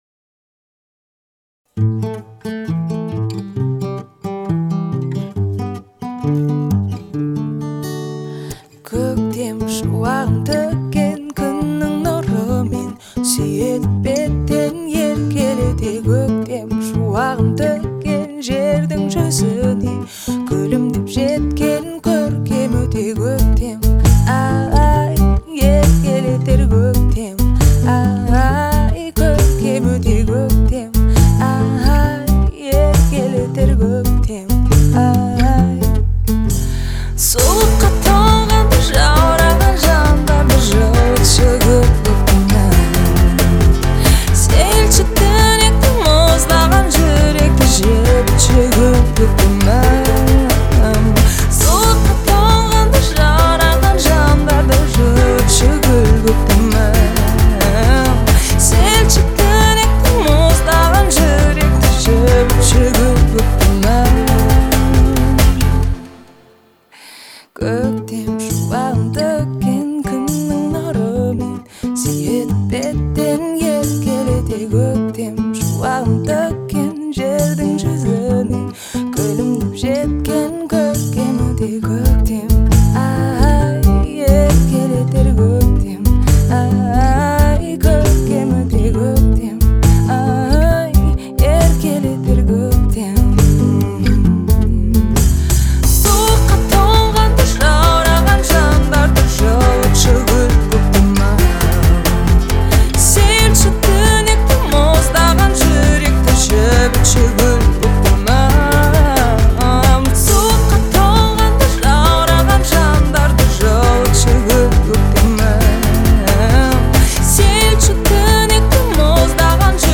казахской поп-музыки, наполненный теплом и нежностью